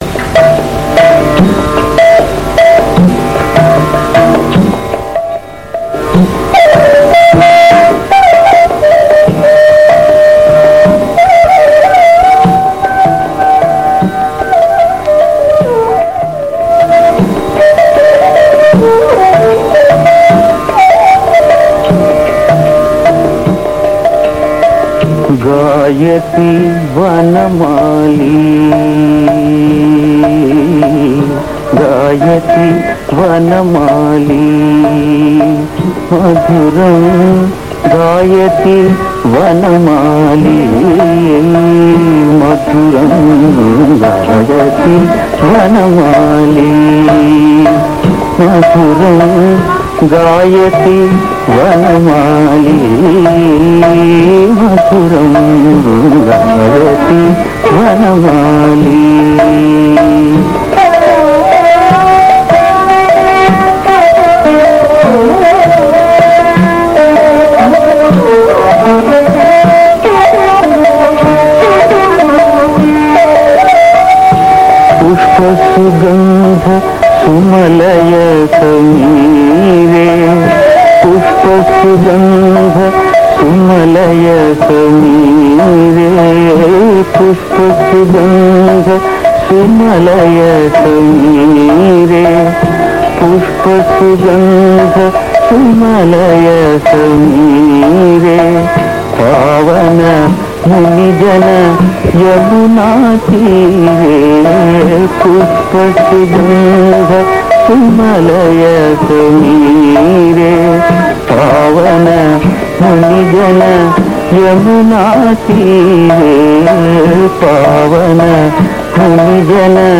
Ragam - Hamsadhwani